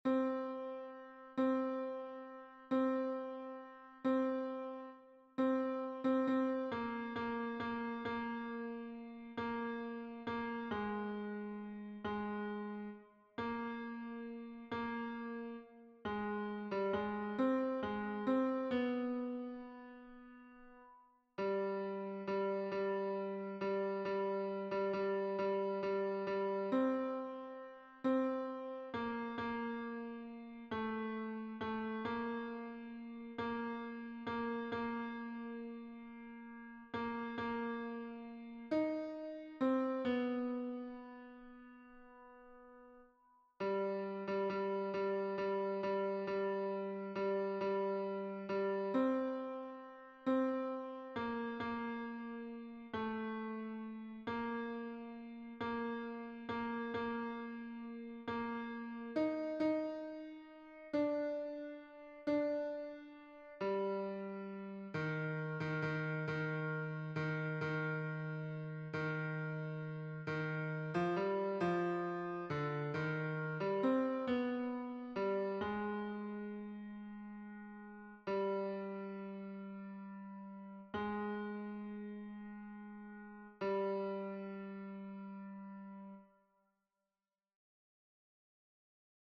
Version piano
Tenor